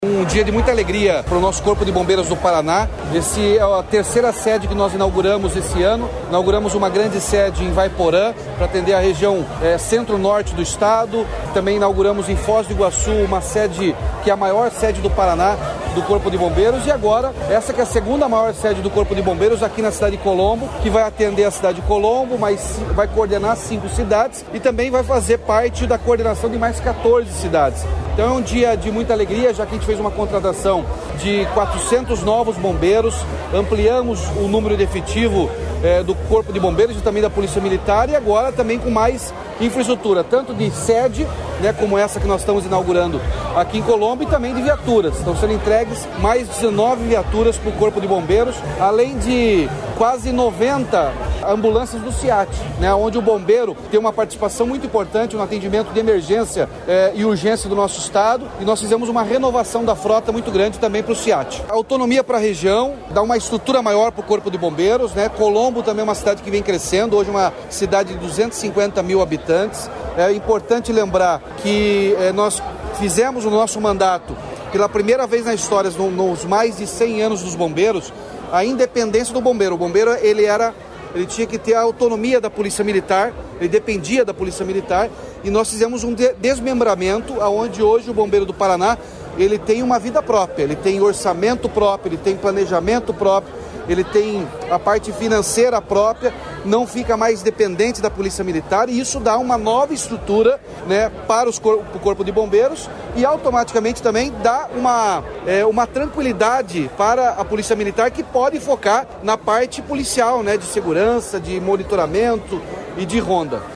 Sonora do governador Ratinho Junior sobre a inauguração do segundo maior quartel do Corpo de Bombeiros, em Colombo